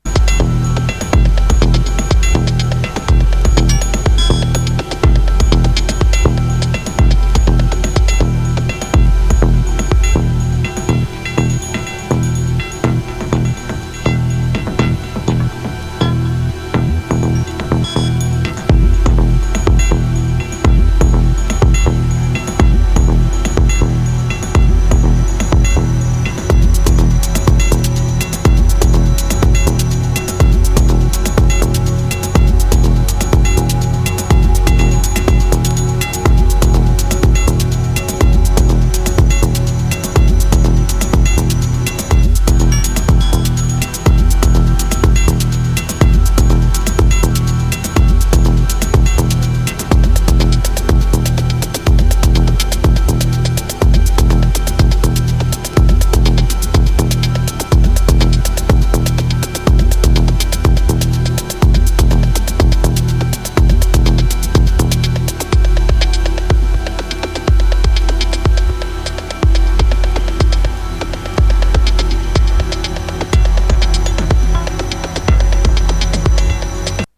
Ambient Techno / Dance Electronica / IDM